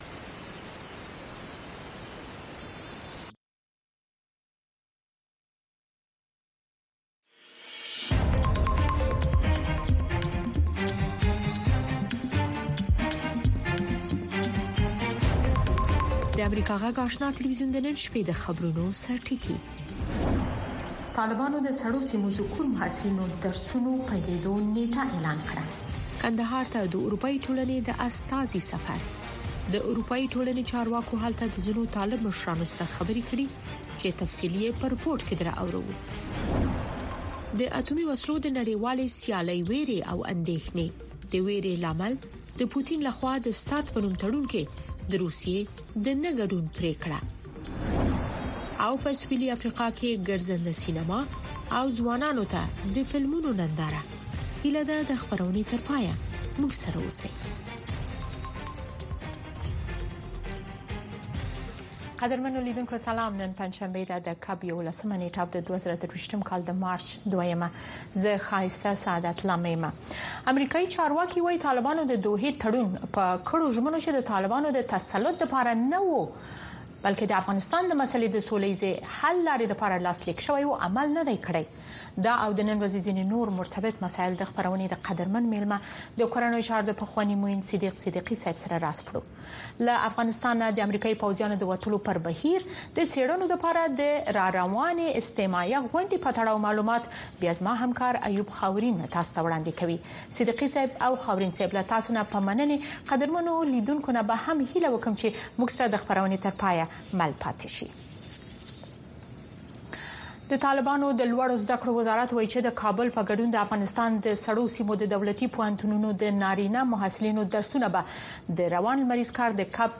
د اشنا خبري خپرونه
د اشنا په خبري خپرونو کې د شنبې څخه تر پنجشنبې پورې د افغانستان، سیمې او نړۍ تازه خبرونه، او د ټولې نړۍ څخه په زړه پورې او معلوماتي رپوټونه، د مسولینو او کارپوهانو مرکې، ستاسې غږ او نور مطالب د امریکاغږ راډیو، سپوږمکۍ او ډیجیټلي شبکو څخه لیدلی او اوریدلی شی.